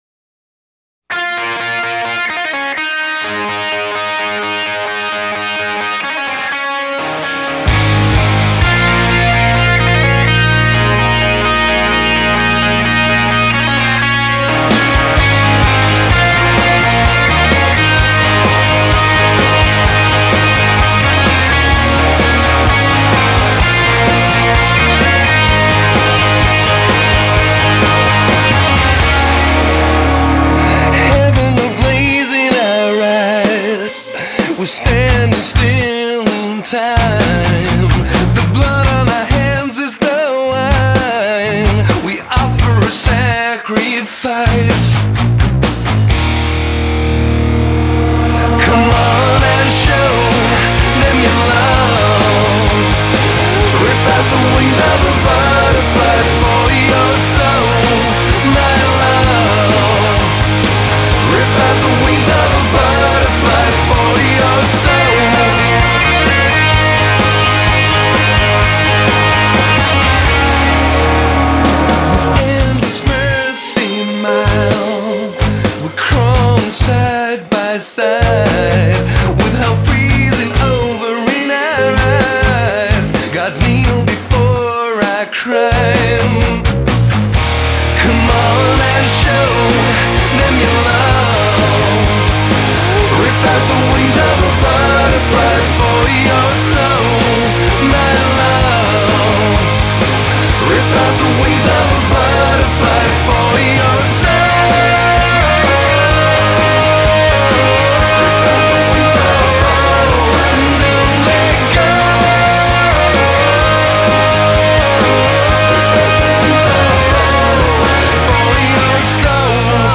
آهنگ راک